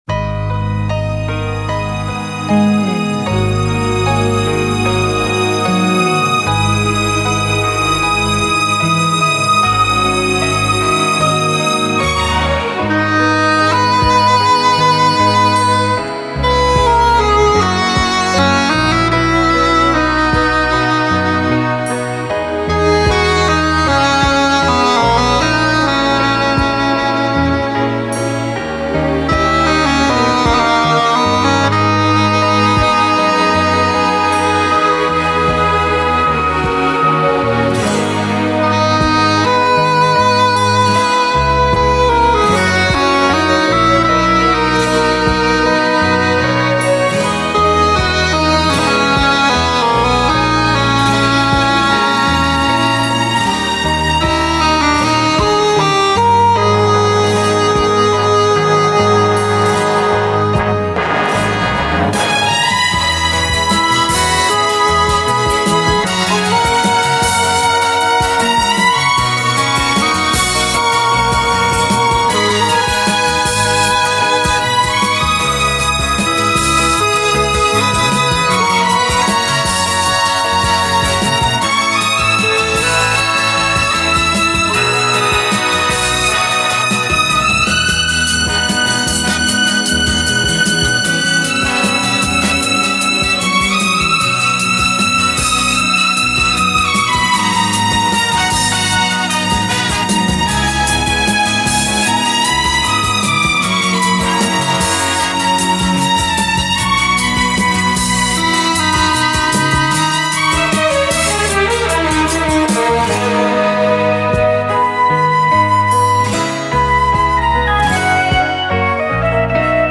инструментальный вариант